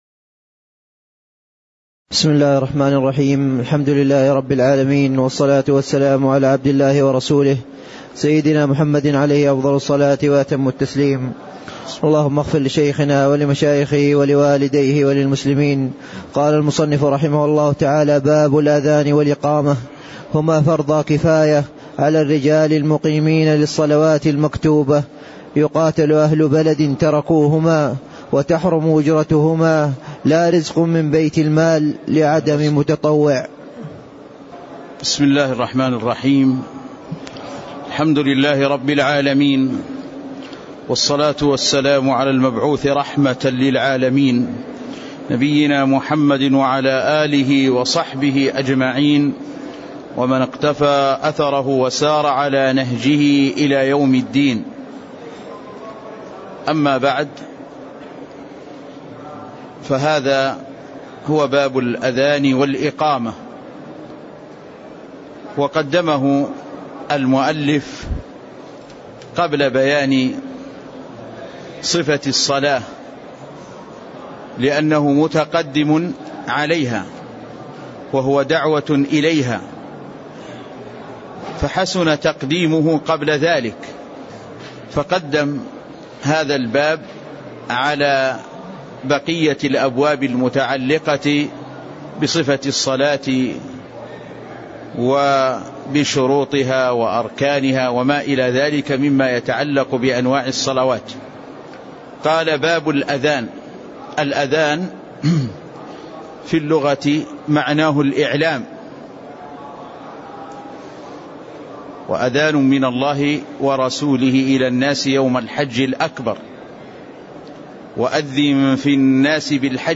تاريخ النشر ٥ رجب ١٤٣٥ هـ المكان: المسجد النبوي الشيخ